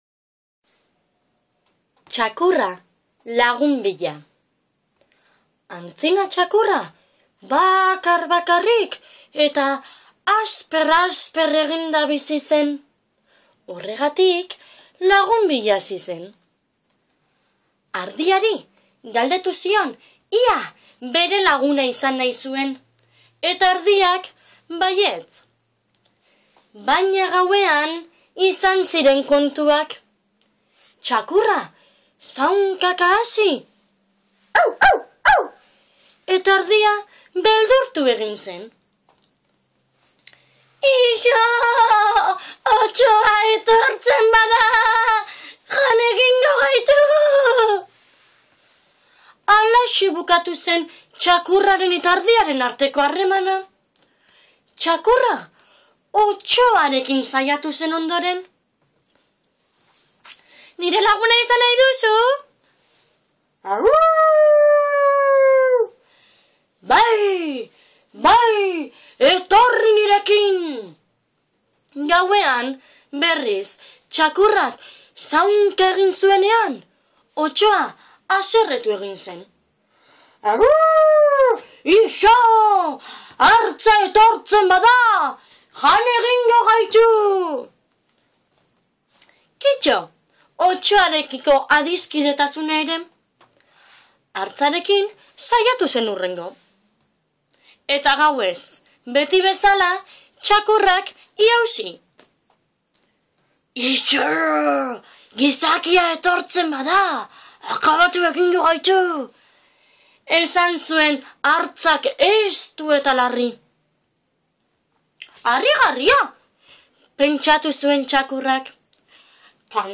ipuin-kontaketa